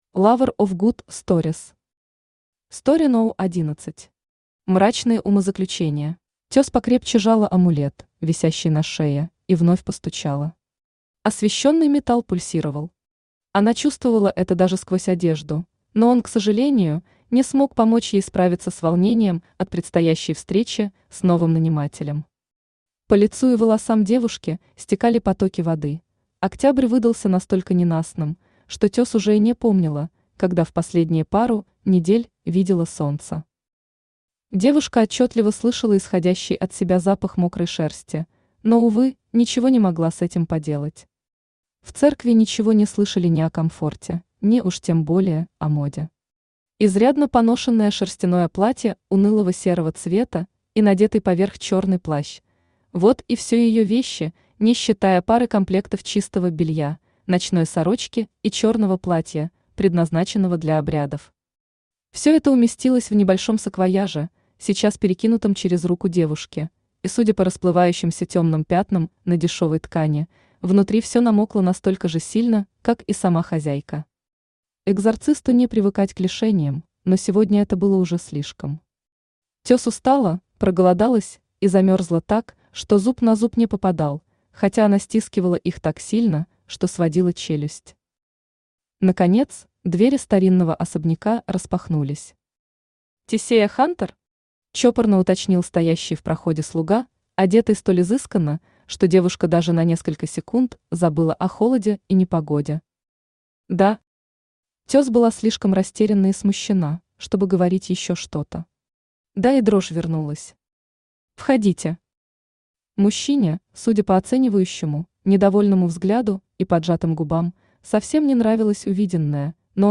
Aудиокнига Story № 11. Мрачные умозаключения Автор Lover of good stories Читает аудиокнигу Авточтец ЛитРес.